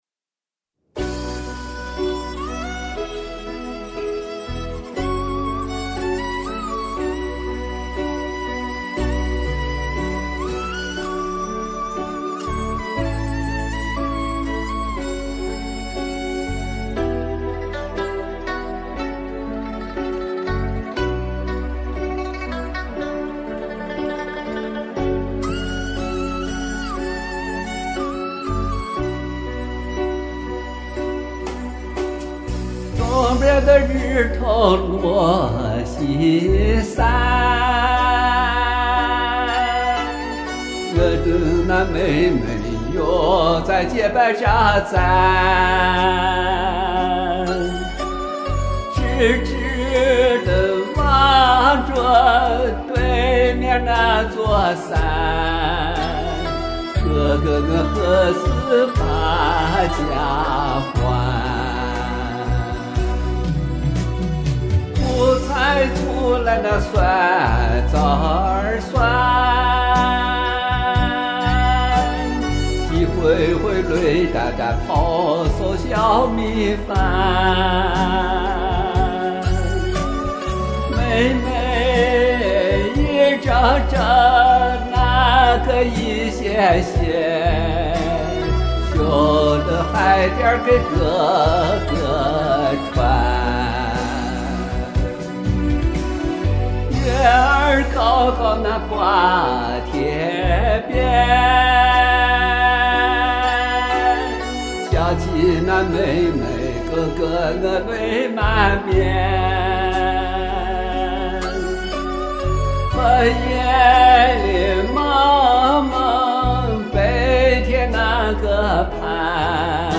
真像是站在黄土高坡上，对着远方的沟壑抒发全部情感，把那份苍凉和思念都喊出来了。
特喜欢陕北民歌调调，浓烈的情感，悲凉的情绪，动人！
陕北风味浓厚，高音了得，大声喝彩！